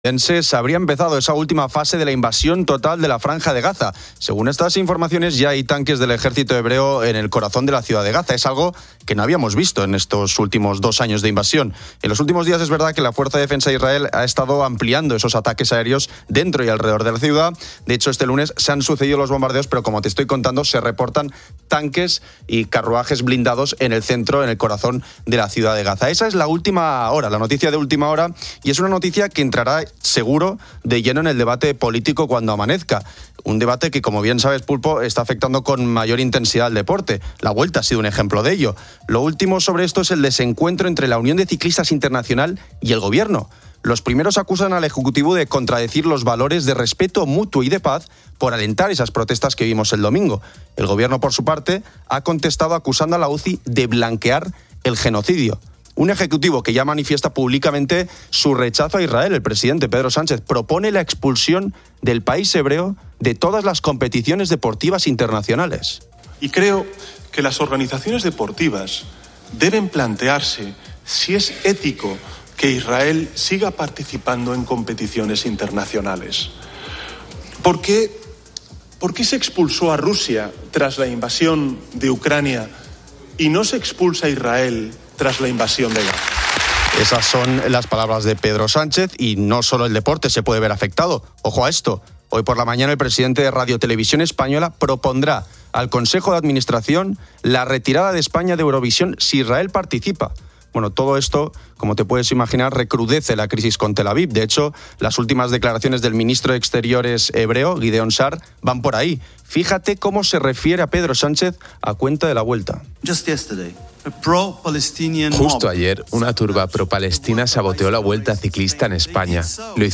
Los oyentes participan en la 'ronda de ponedores' compartiendo sus profesiones.